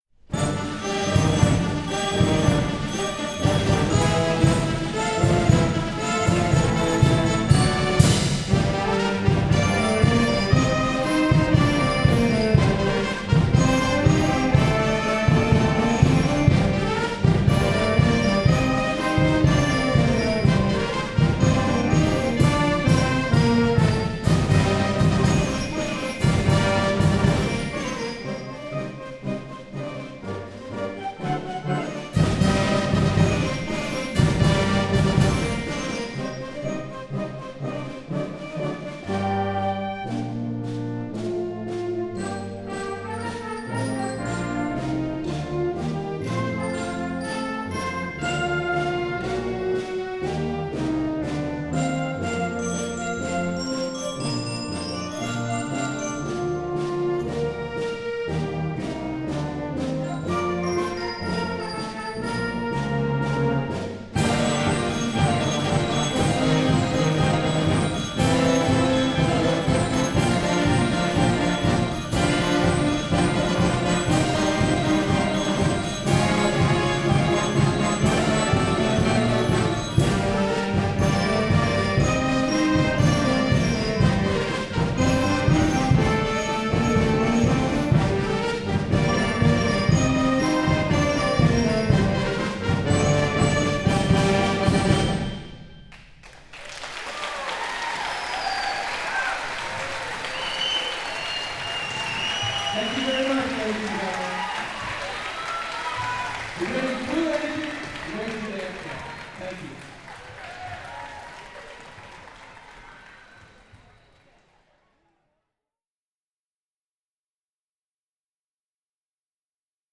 Grade:  2
Flute
Clarinet
Bass Clarinet
Alto Sax
Tenor Sax
Bari Sax
Cornet (3)
F Horn (2)
Trombone (3)
Euphonium
Tuba
Percussion